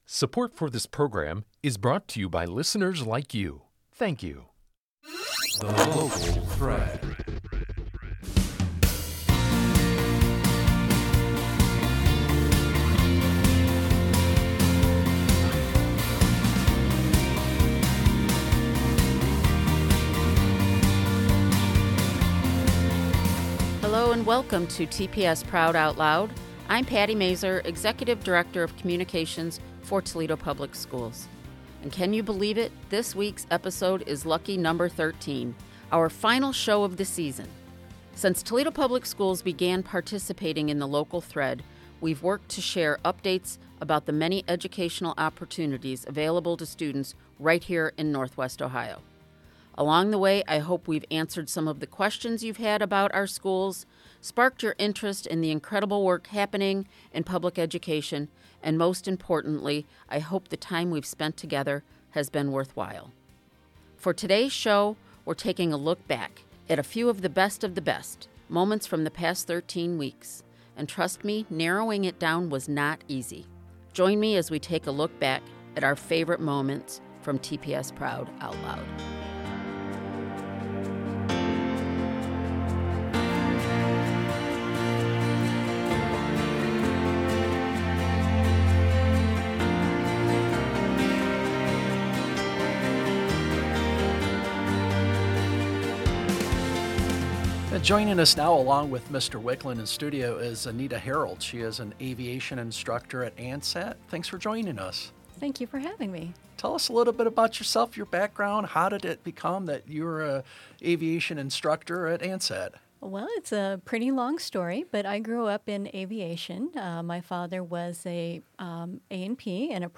On this season finale, we take a look back at a few of our favorite interviews.